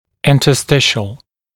[ˌɪntə’stɪʃl][ˌинтэ’стишл]интерстициальный, межуточный (о ткани), внутритканевый